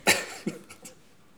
rire_09.wav